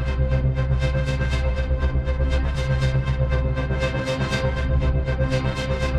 Index of /musicradar/dystopian-drone-samples/Tempo Loops/120bpm
DD_TempoDroneB_120-C.wav